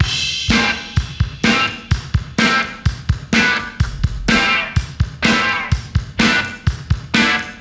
• 126 Bpm 1990s Pop-Folk Breakbeat Sample A Key.wav
Free drum groove - kick tuned to the A note. Loudest frequency: 2071Hz